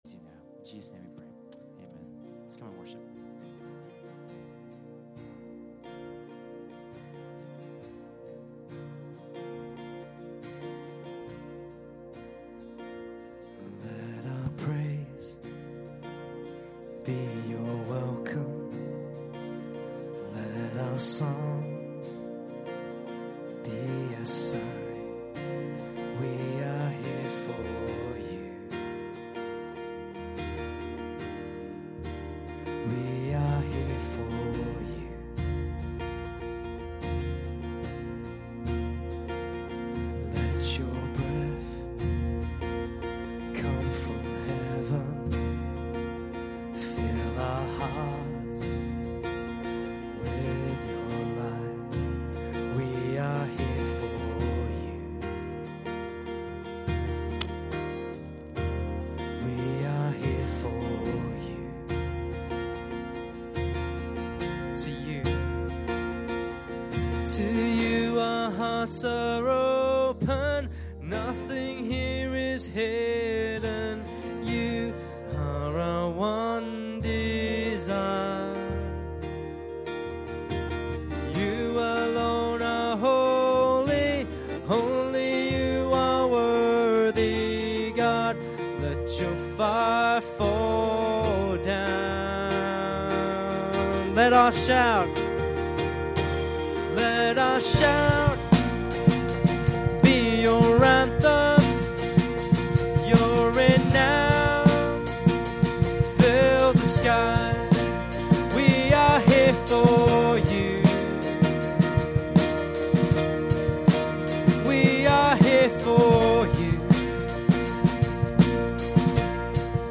Worship August 20, 2017